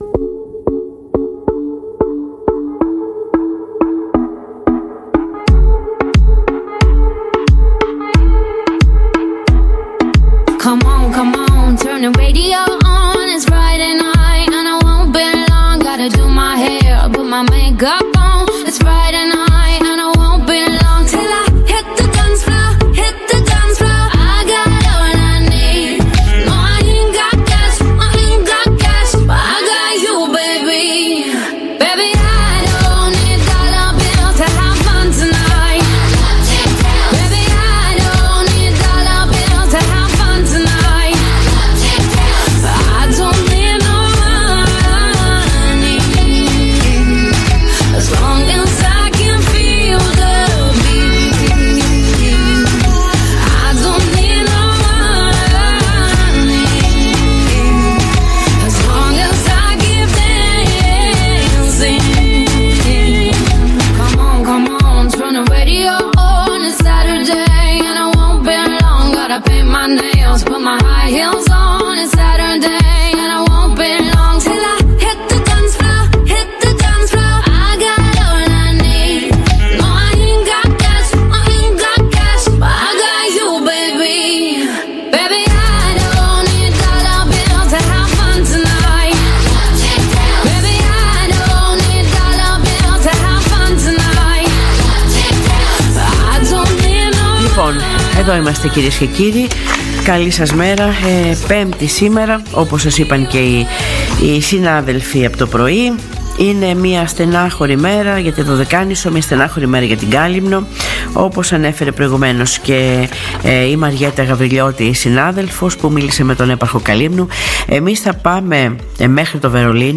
Στην ΙΤΒ BERLIN τη μεγαλύτερη παγκοσμίως τουριστική έκθεση, συμμετέχει η Αντιδήμαρχος Τουρισμού κ. Καλλιόπη Κουτούζη. Η ίδια, στην εκπομπή ΡΑΔΙΟΠΛΟΕΣ αναφέρεται στις τεράστιες προσπάθειες των Δωδεκανησίων εκπροσώπων, προκειμένου να πεισθούν οι μελλοντική τουρίστες για την ασφάλεια των επισκεπτών στην Ελλάδα.